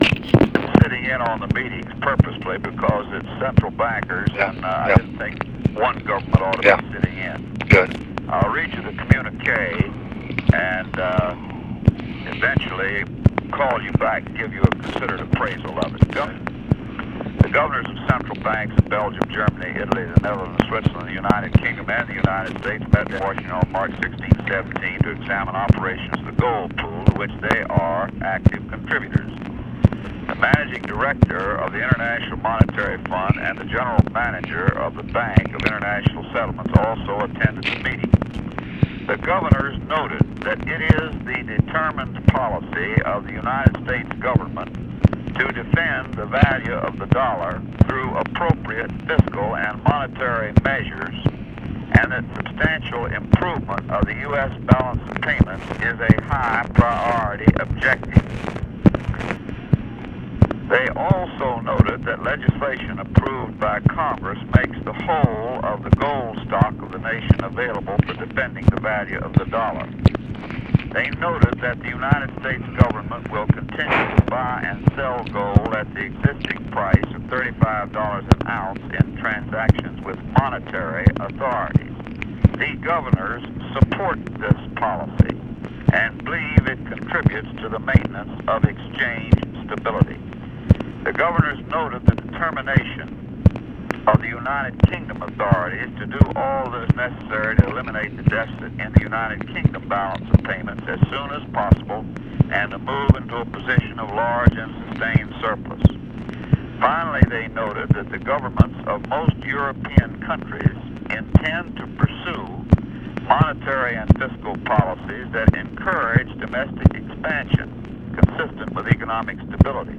Conversation with HENRY FOWLER and WILLIAM MCC. MARTIN, March 17, 1968